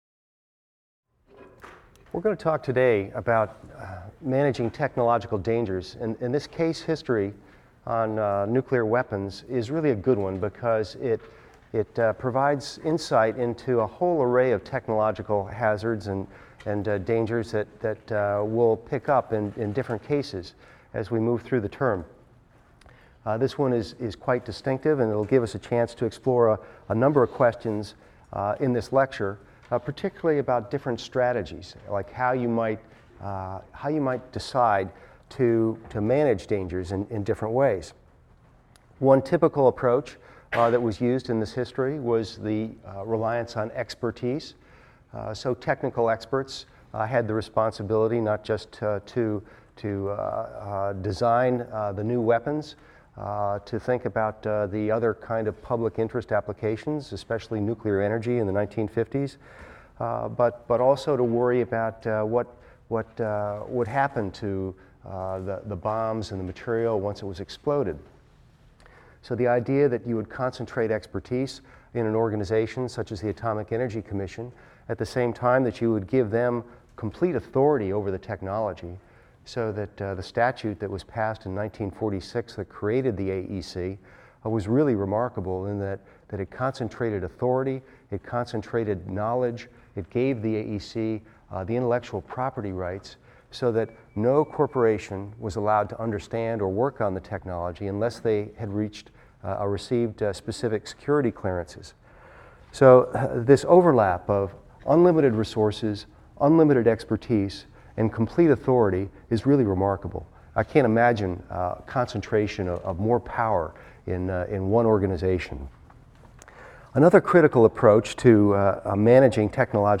EVST 255 - Lecture 4 - Nuclear Secrecy and Ecology | Open Yale Courses